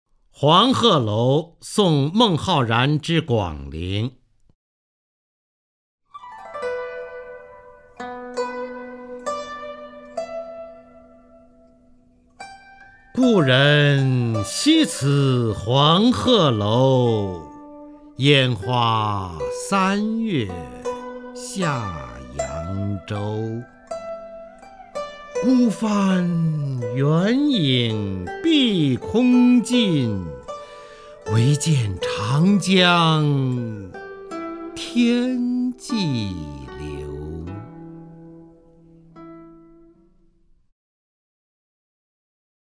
方明朗诵：《黄鹤楼送孟浩然之广陵》(（唐）李白) （唐）李白 名家朗诵欣赏方明 语文PLUS